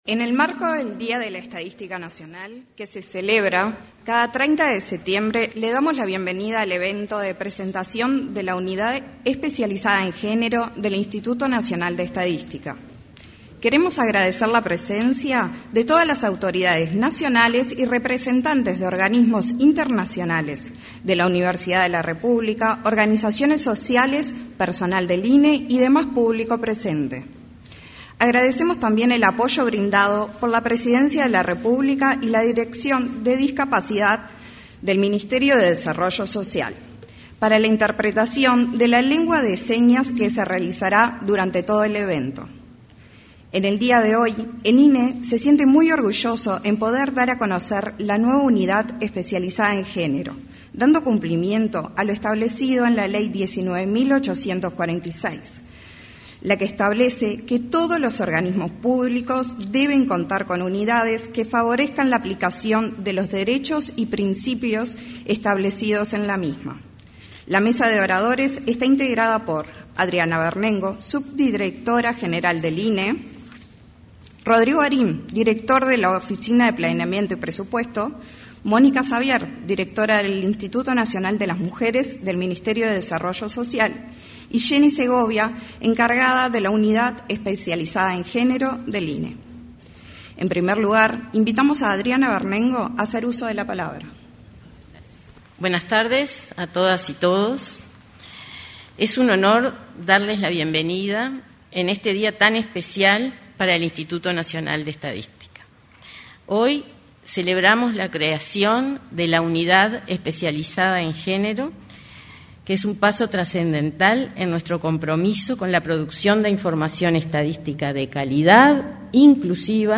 Presentación de la Unidad Especializada en Género del INE 29/09/2025 Compartir Facebook X Copiar enlace WhatsApp LinkedIn Con motivo de la presentación de la Unidad Especializada en Género del Instituto Nacional de Estadística (INE), se expresaron la subdirectora de dicho organismo, Adriana Vernengo; la directora del Instituto Nacional de las Mujeres, Mónica Xavier, y el director de la Oficina de Planeamiento y Presupuesto, Rodrigo Arim.